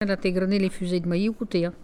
Elle provient de Saint-Jean-de-Monts.
Catégorie Locution ( parler, expression, langue,... )